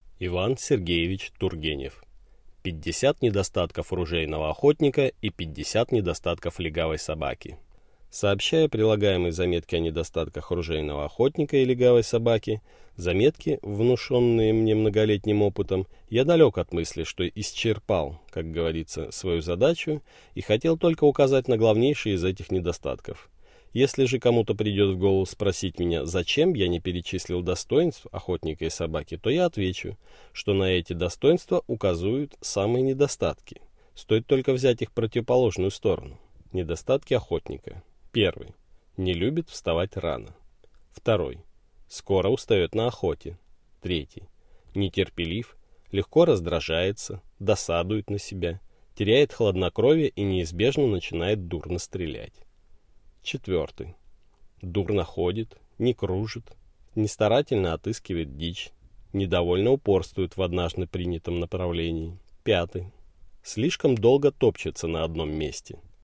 Аудиокнига Пятьдесят недостатков ружейного охотника и пятьдесят недостатков легавой собаки | Библиотека аудиокниг